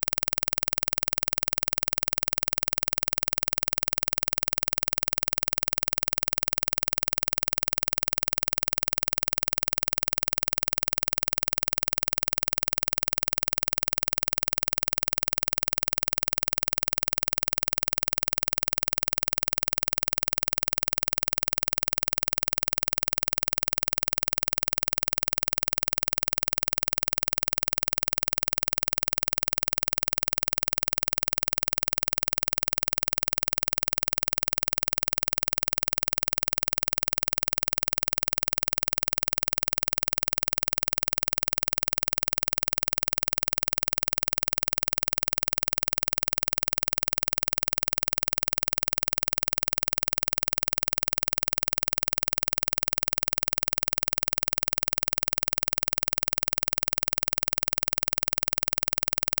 Click track
Test signal to simulate vocal fry:
Mono file, plays on both channels:
impulse-train-20.flac